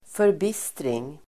Ladda ner uttalet
Uttal: [förb'is:tring]